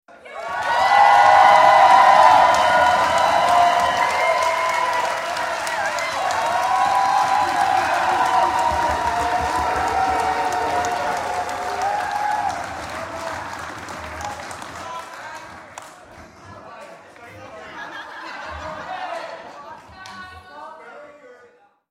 Овации в честь свадьбы